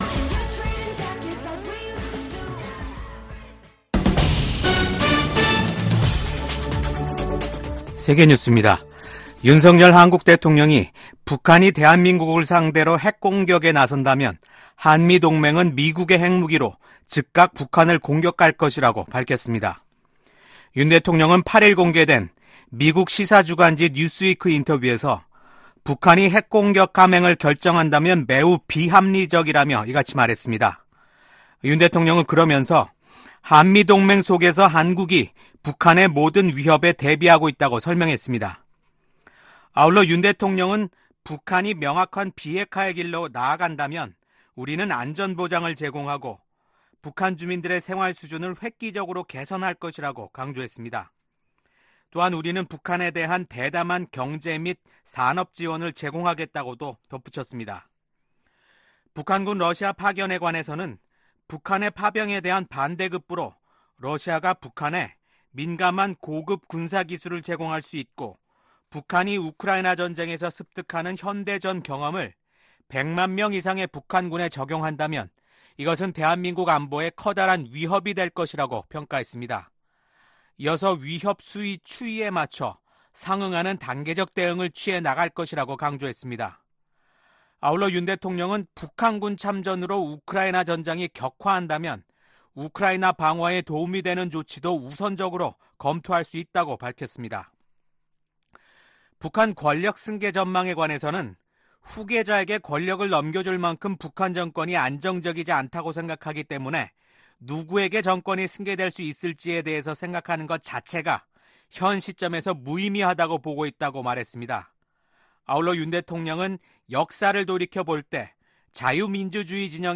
VOA 한국어 아침 뉴스 프로그램 '워싱턴 뉴스 광장'입니다. 조 바이든 미국 대통령이 미국인들의 선택을 수용해야 한다며 도널드 트럼프 당선인의 대선 승리를 축하했습니다. 블라디미르 푸틴 러시아 대통령은 북한과의 합동 군사훈련이 가능하다고 밝혔습니다. 미국 국방부는 러시아에 파병된 북한군이 전장에 투입되면 합법적인 공격 대상이 된다고 경고했습니다.